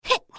slayer_f_voc_skill_piercingfang.mp3